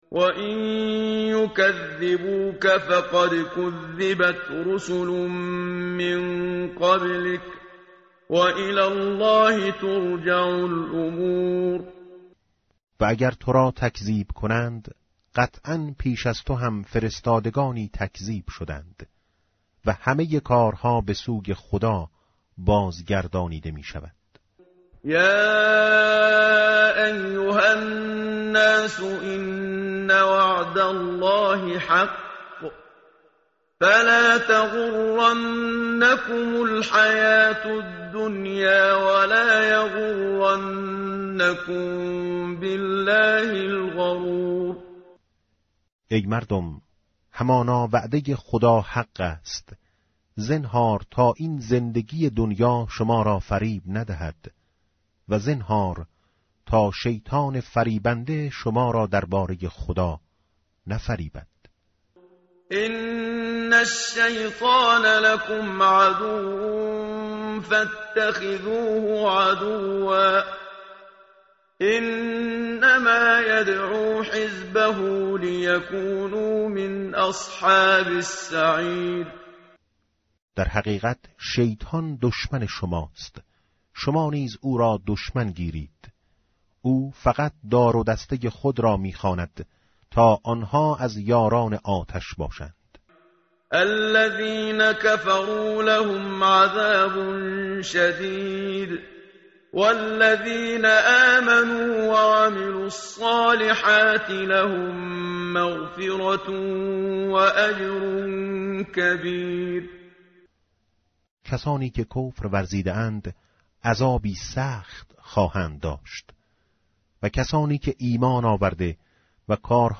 متن قرآن همراه باتلاوت قرآن و ترجمه
tartil_menshavi va tarjome_Page_435.mp3